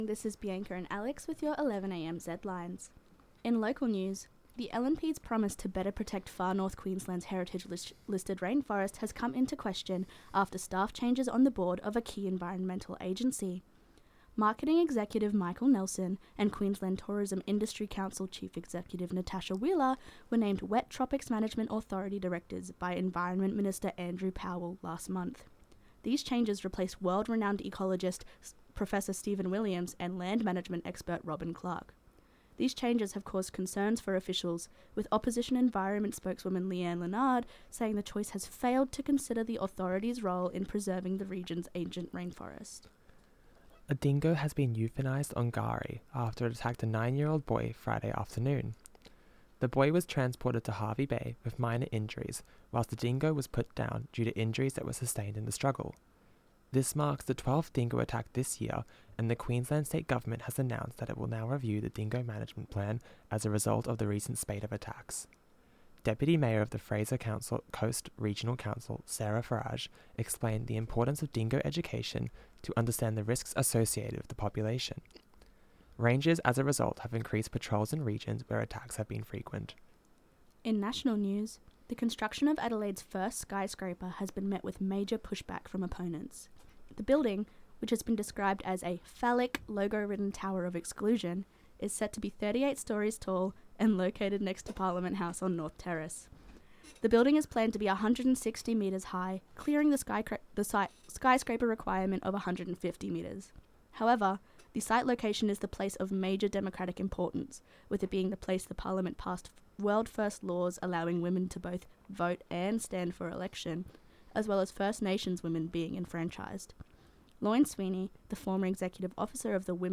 From Peter Malinauskas’ Facebook Zedlines Bulletin 11am Zedlines Tuesday 27.5.25.mp3